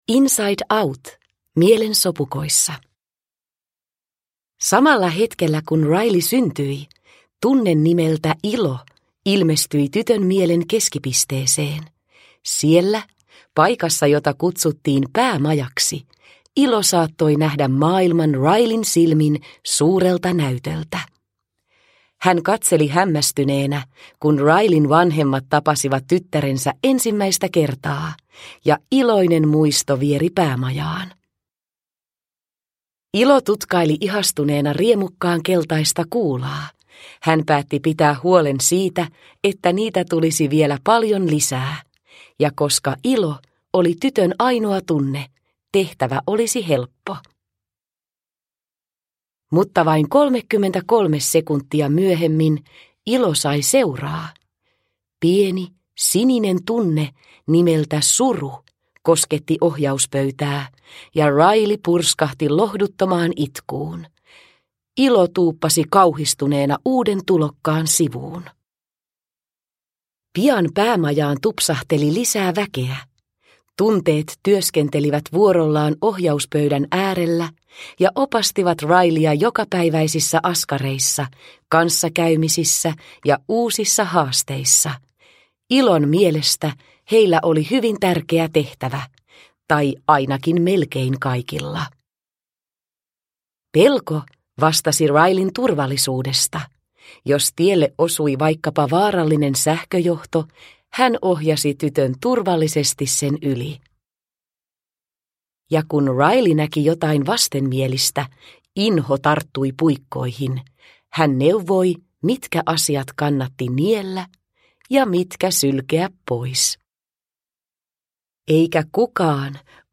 Inside Out. Satuklassikot – Ljudbok – Laddas ner